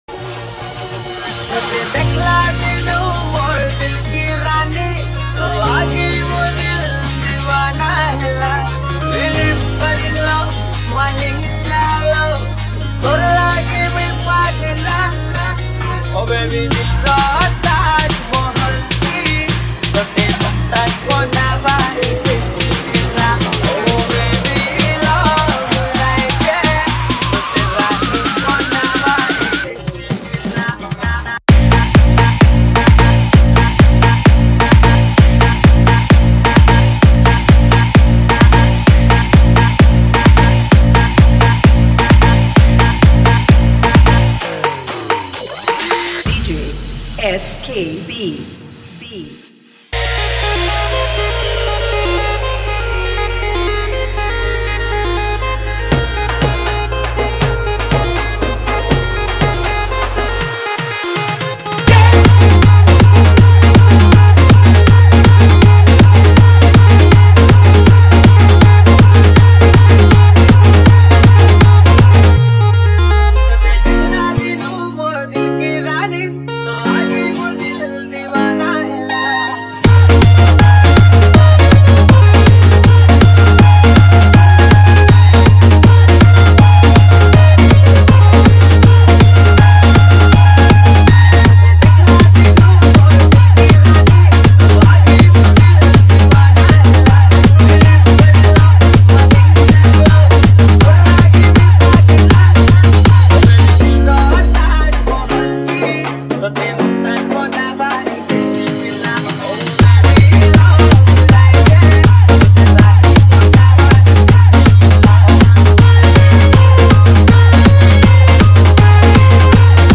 Humming Dance Remix